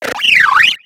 Cri de Déflaisan dans Pokémon X et Y.